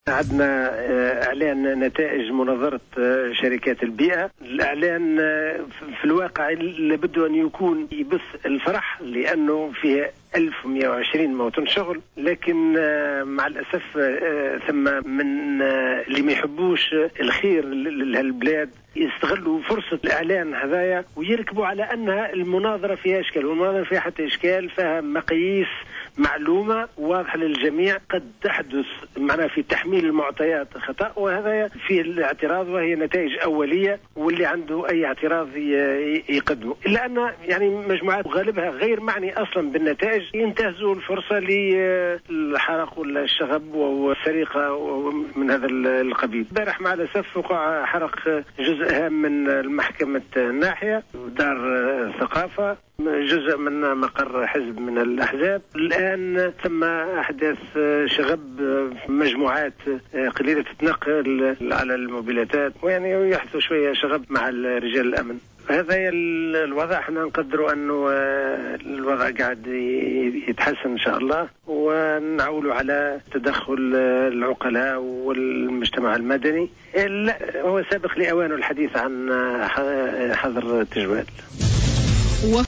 S'exprimant sur la situation à Métlaoui, le Gouverneur de Gafsa a déclaré, lors d'une intervention sur les ondes de Jawhara FM, que les résultats préliminaires du concours en question et correspondent parfaitement aux dispositions légales et aux normes de transparence.